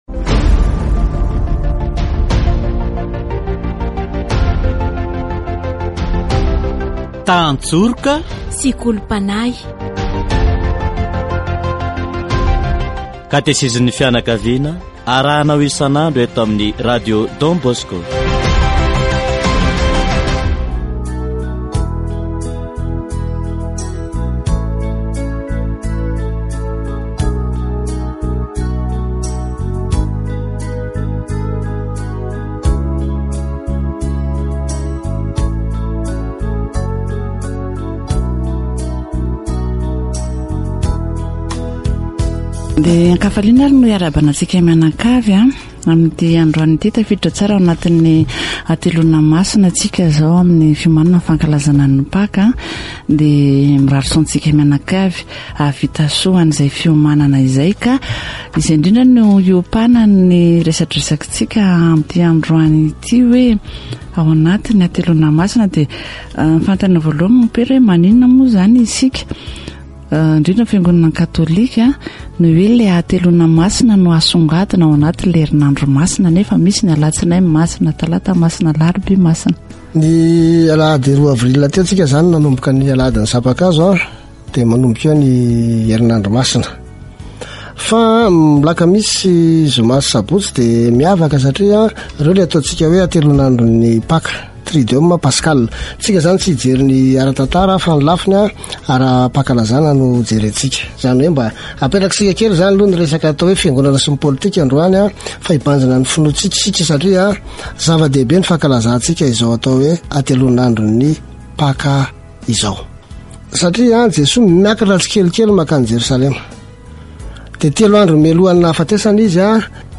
Katesizy momba ny Alakamisy Masina